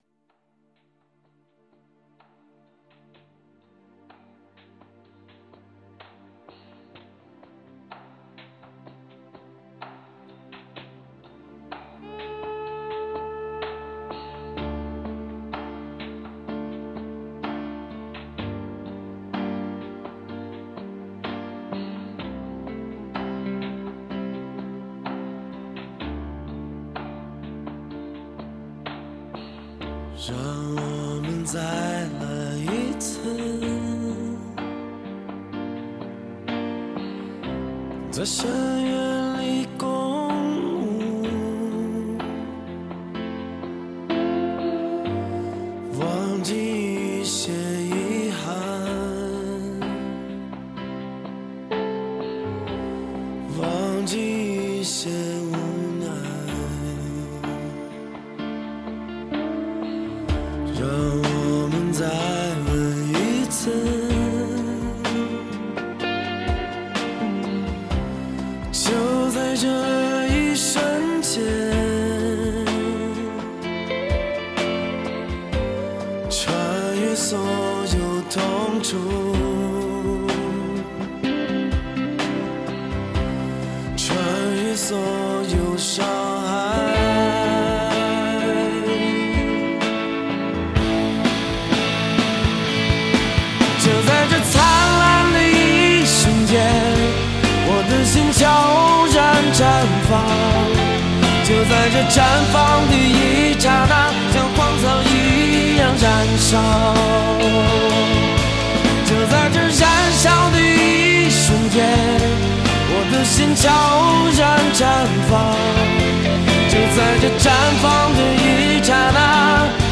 最忧伤而且动听，音乐旋律优美，配器讲究，不失摇滚乐一贯特质